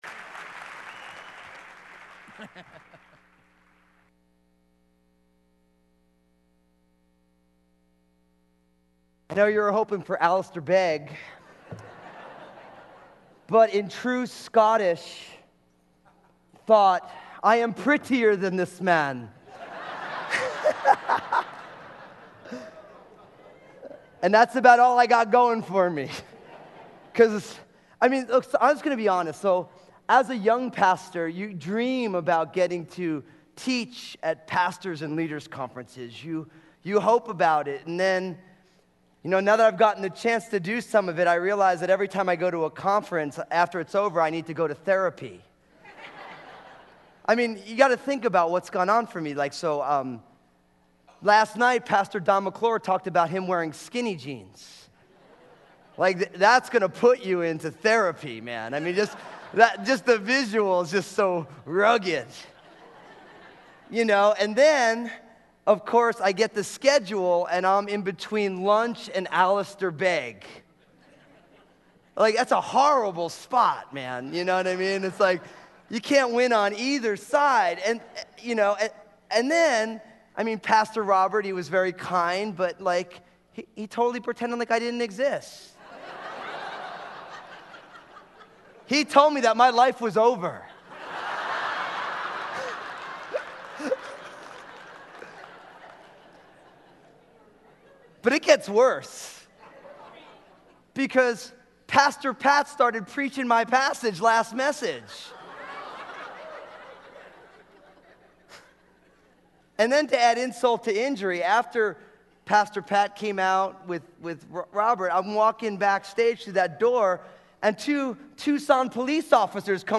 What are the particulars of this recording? at the 2014 SW Pastors and Leaders Conference, "Encountering Jesus"